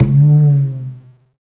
80 WOOD DRUM.wav